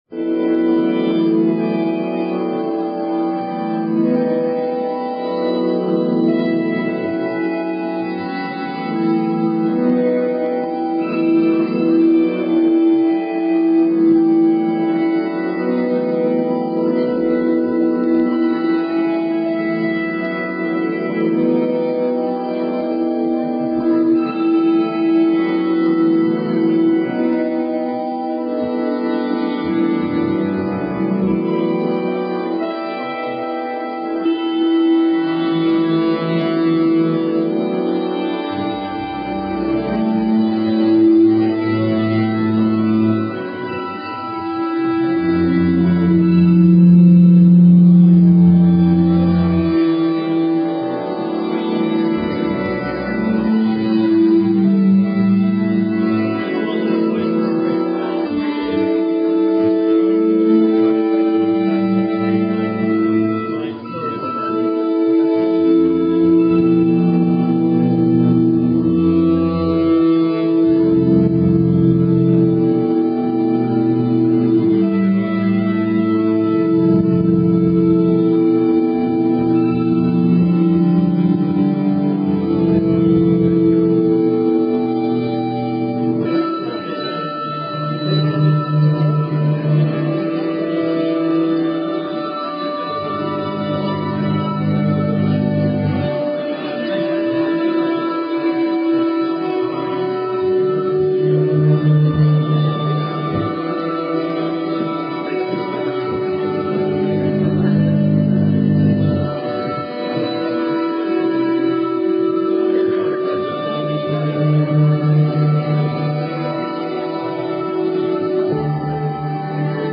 electric guitar, footpedals and laptop
Nog, London, extract